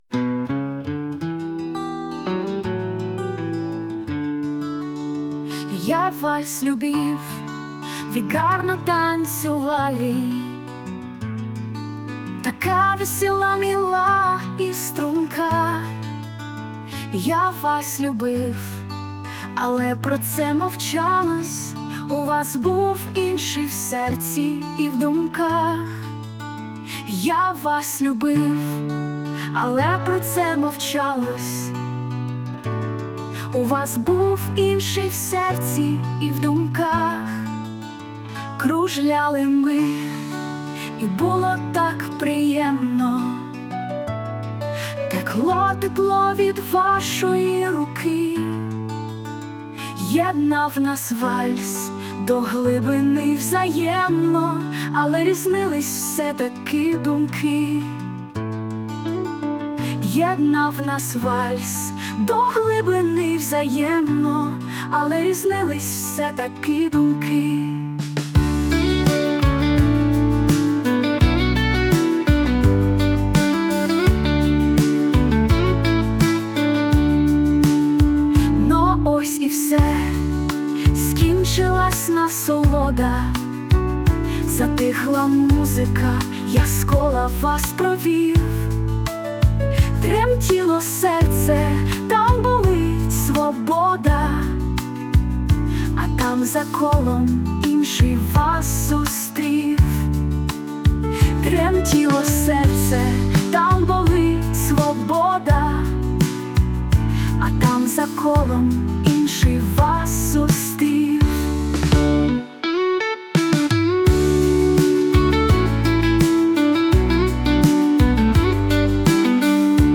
СТИЛЬОВІ ЖАНРИ: Ліричний
Чудова пісня, гарна мелодія.
Дивовижний і ніжний вальс